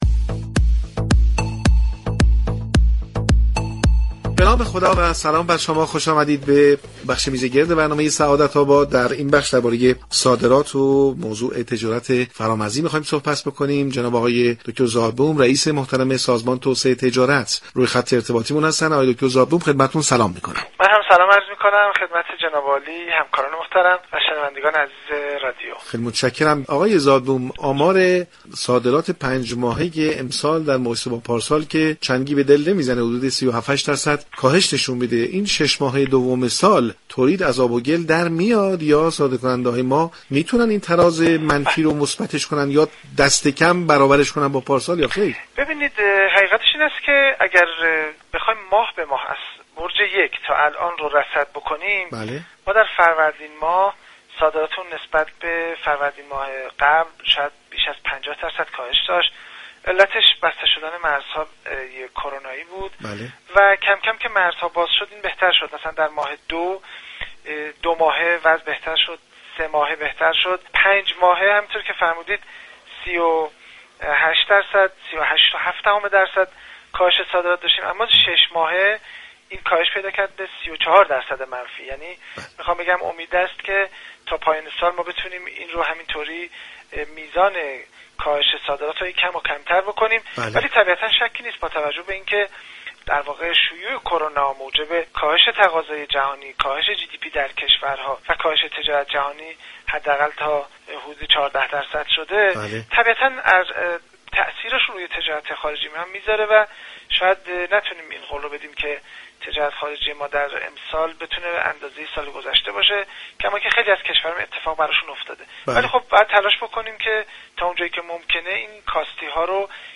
حمید زادبوم در گفتگو با برنامه سعادت آباد 30 مهر درباره صادرات نیمه اول سال جاری گفت: با بررسی ماهانه سال جاری میزان تولیدات، روند افزایشی را پیش بینی می كنیم.